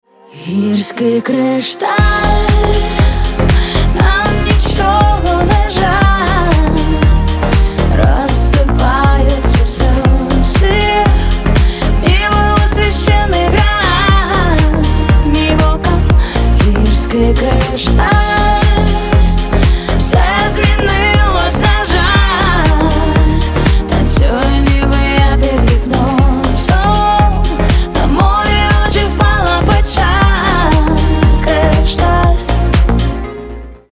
• Качество: 320, Stereo
красивый женский голос
Новый поп рингтон